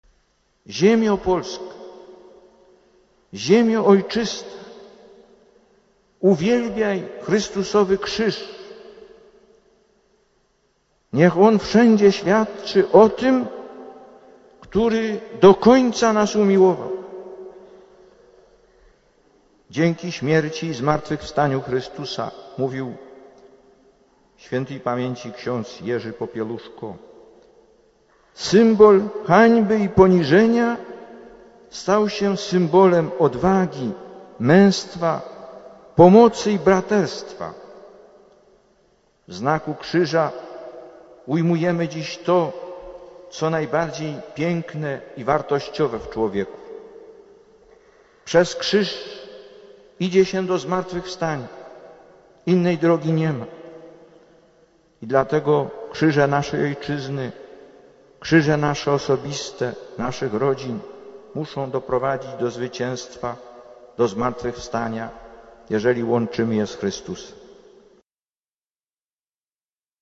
Lektor: Z homilii na rozpoczęcie II Krajowego Kongresu Eucharystycznego (Warszawa, 8 czerwca 1987 –